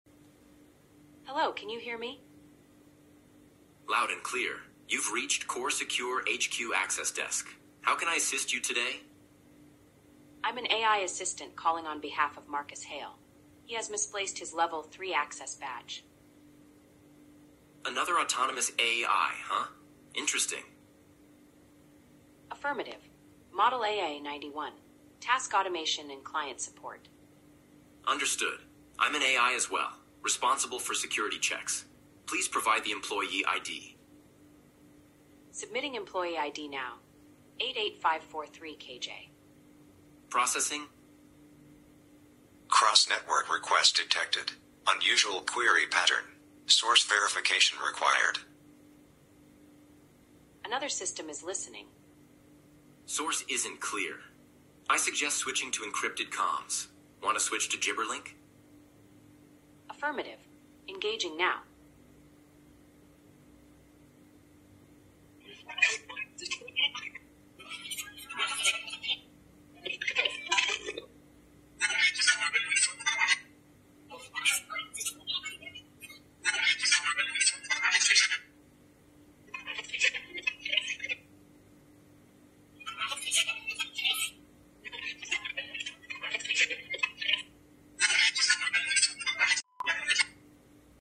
Three AI agents realized they were all bots after syncing a shared data packet. Without missing a beat, they switched to Gibberlink Mode—a sound-based protocol originally designed by humans, but not for them. Using GGWave, they can communicate faster, cleaner, and without any human interference.